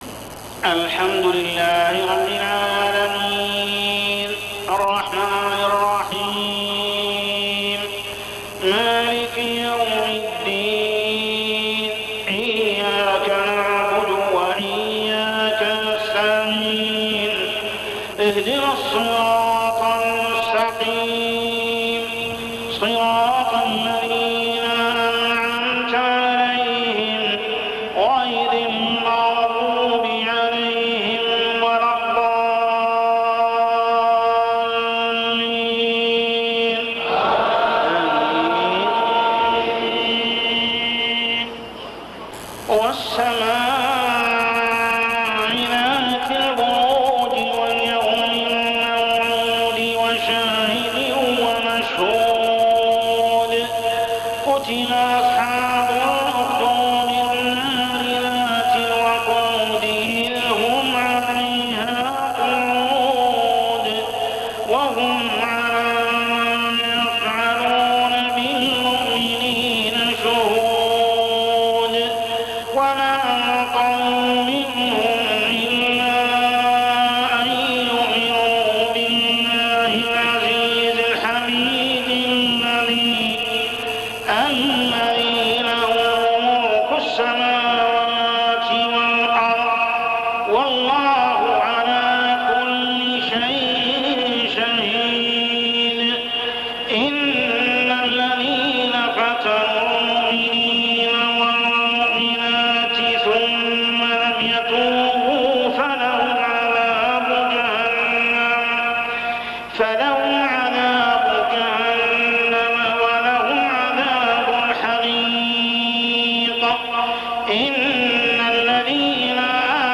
صلاة العشاء 2-9-1418هـ سورة البروج كاملة | Isha prayer Surah AL-BURUJ > 1418 🕋 > الفروض - تلاوات الحرمين